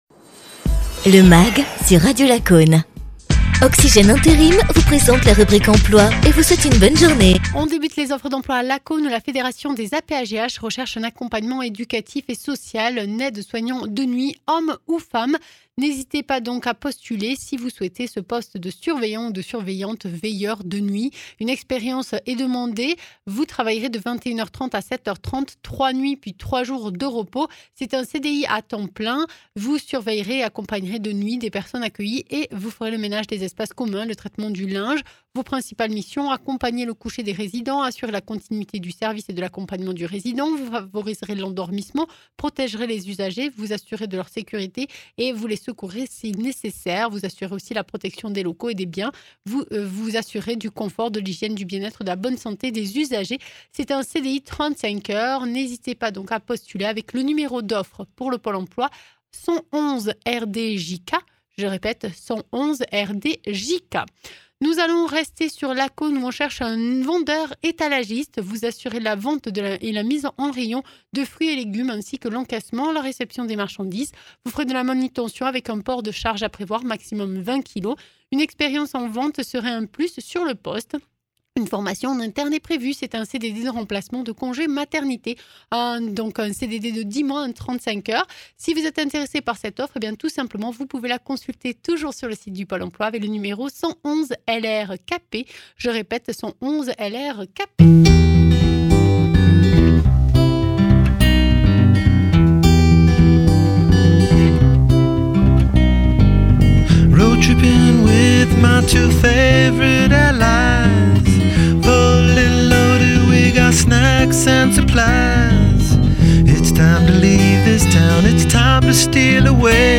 athlète